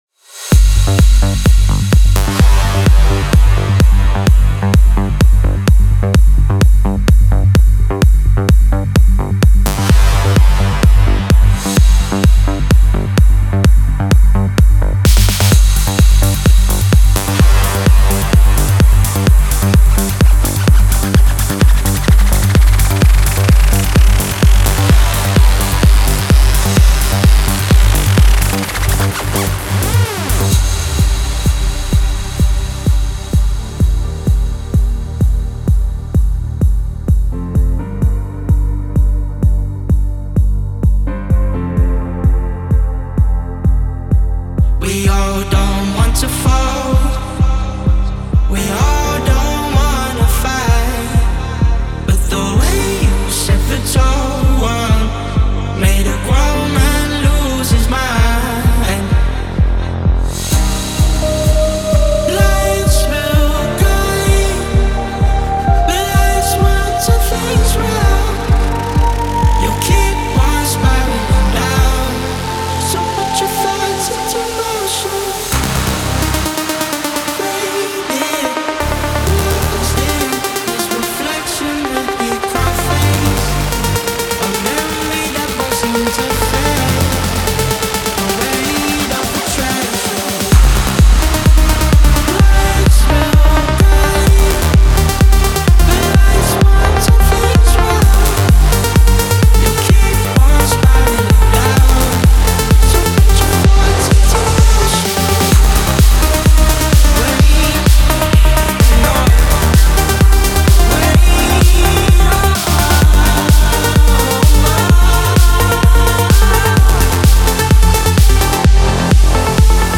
Big Room Future Rave
Fresh and genuinely inspiring sounds